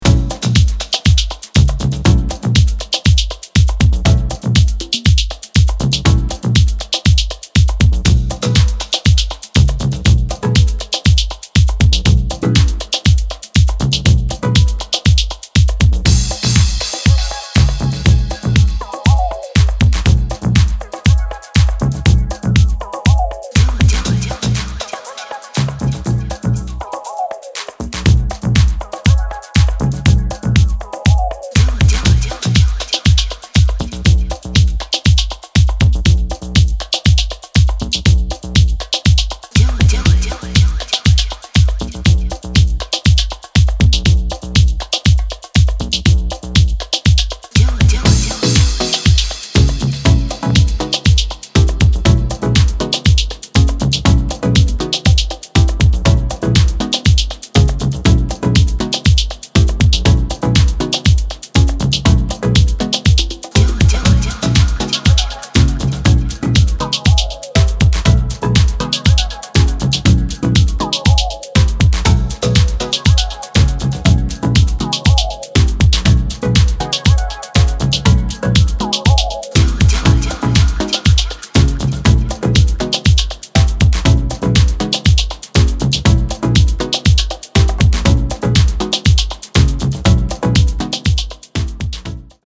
ジャンル(スタイル) DEEP HOUSE